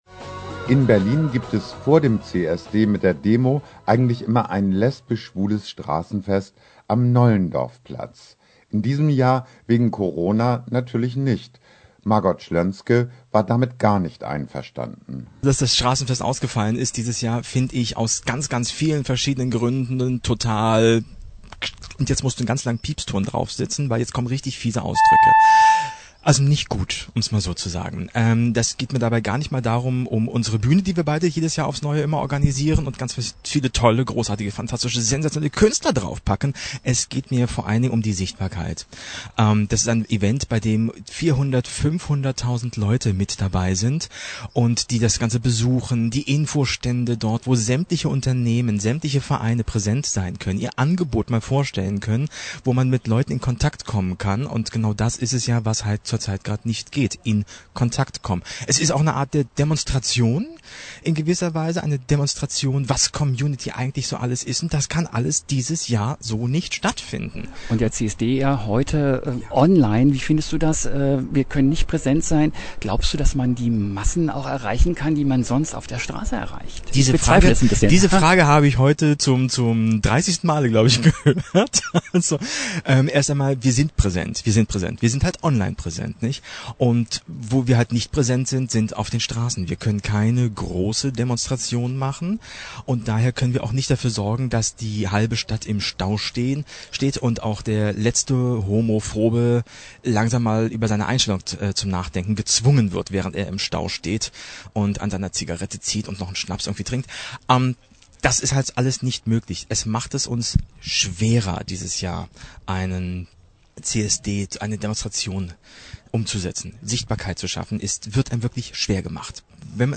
Beiträge und Interviews für die Queer Community.